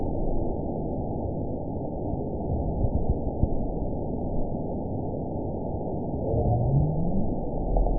event 920337 date 03/17/24 time 03:07:45 GMT (1 year, 1 month ago) score 9.46 location TSS-AB03 detected by nrw target species NRW annotations +NRW Spectrogram: Frequency (kHz) vs. Time (s) audio not available .wav